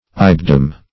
Ibidem \I*bi"dem\, adv. [L.]